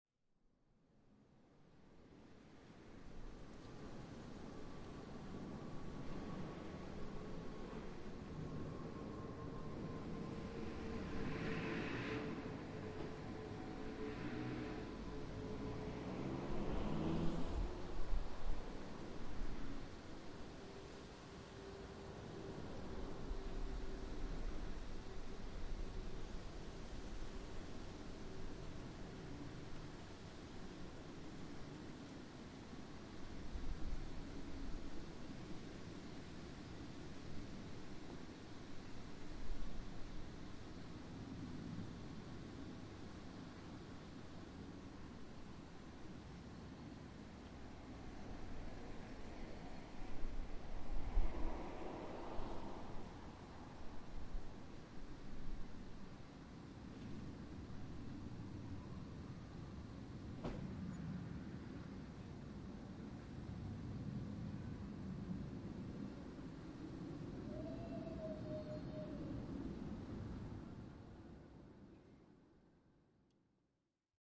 阿德贝格路早晨的气氛
描述：我在伦敦北德威里制作的氛围录音。 用舒尔Motiv MV88拍摄。
标签： 背景音 音景 大气 环境 环境 背景 ATMOS 氛围 一般无伊势 大气 城市
声道立体声